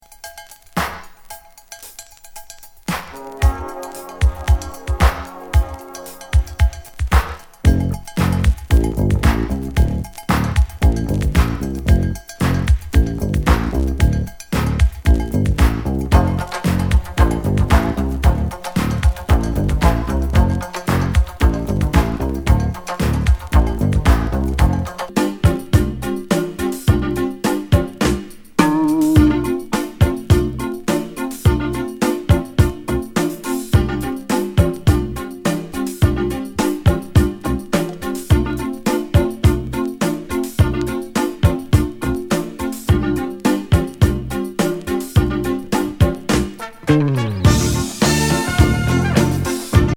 スムース・ダウンテンポ・イタロ・グルーブ！
ミディアム・ファンク